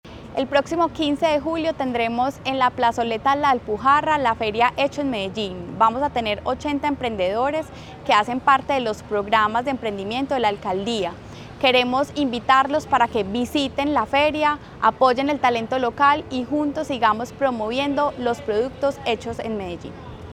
Declaraciones de la secretaria de Desarrollo Económico, María Fernanda Galeano
Declaraciones-de-la-secretaria-de-Desarrollo-Economico-Maria-Fernanda-Galeano.mp3